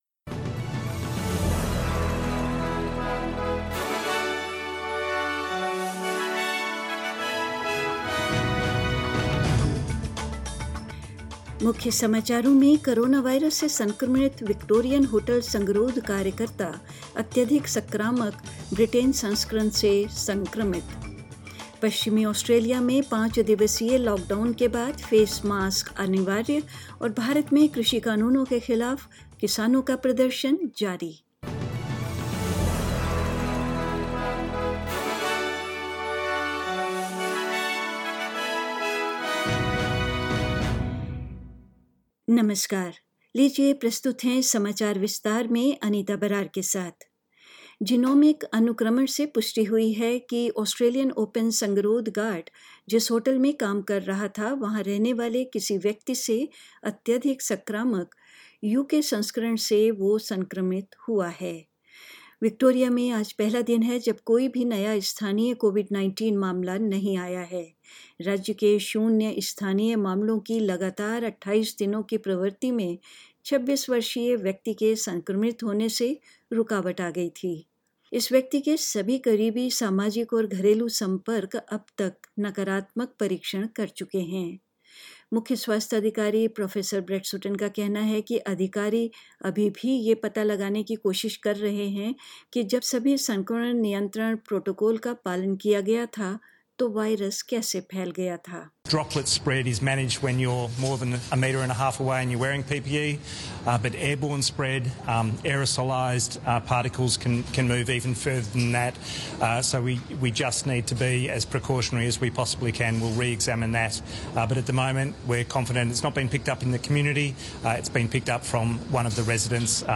News in Hindi: National Cabinet decides to increase the cap on international arrivals